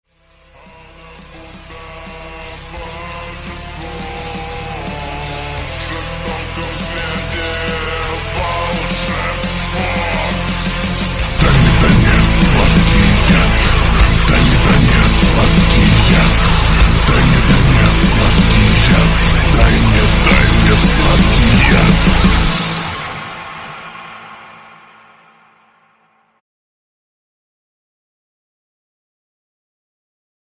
громкие
пугающие
страшные
цикличные